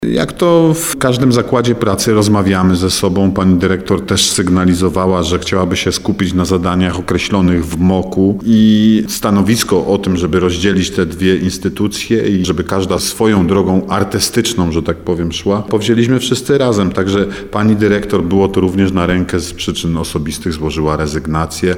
Pani dyrektor było to również na rękę z przyczyn osobistych – wyjaśnił zastępca prezydenta Nowego Sącza Artur Bochenek.